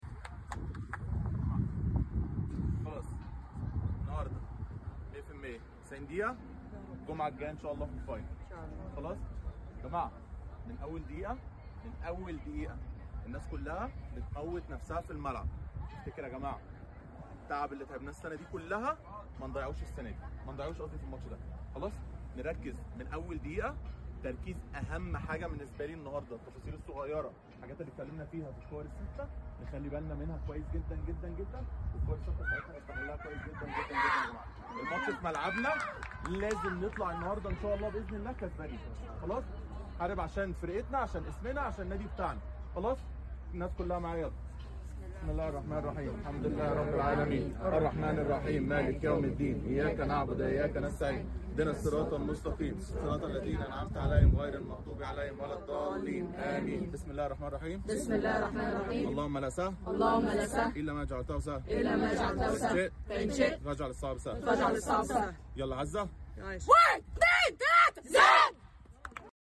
Our coach speech before the sound effects free download